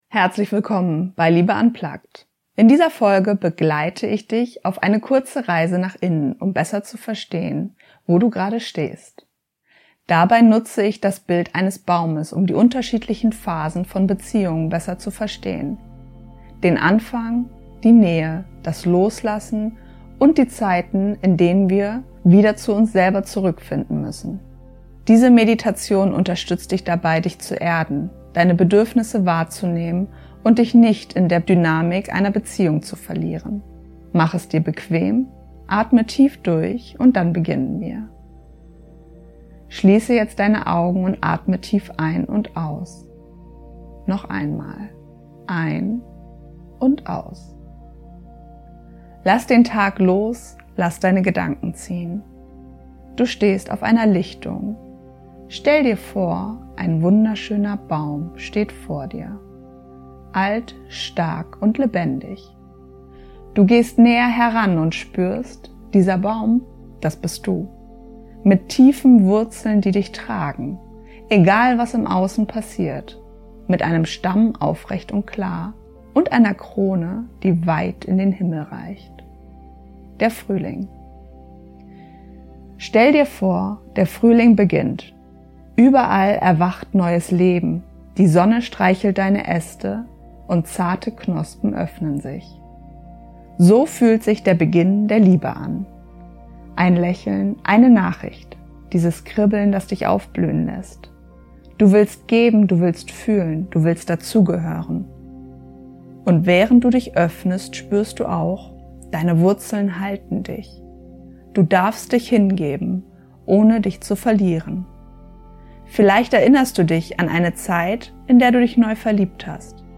Die Meditation, die dich zurück zu dir bringt